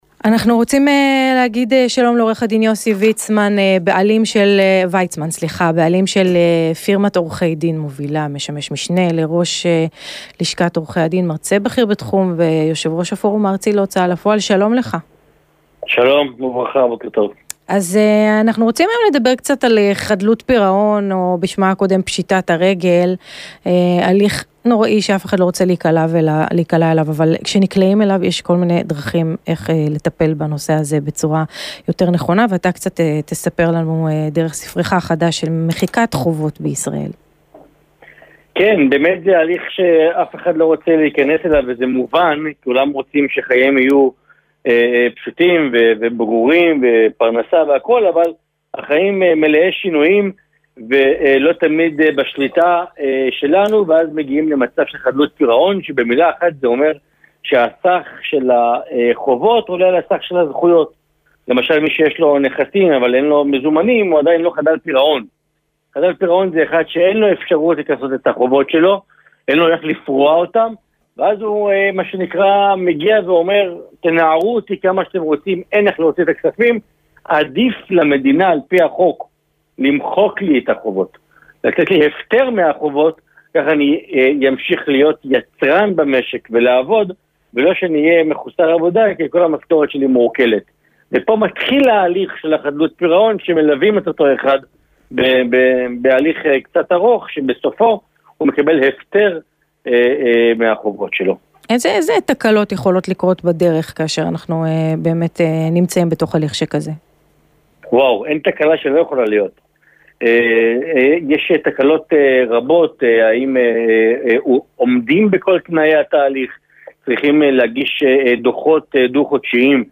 ראיון רדיו